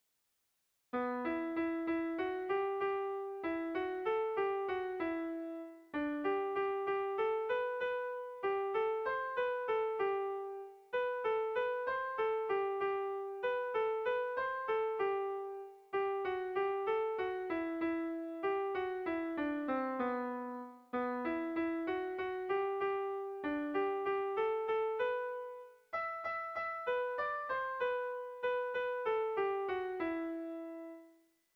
Kontakizunezkoa
Hamabiko txikia (hg) / Sei puntuko txikia (ip)
ABCDE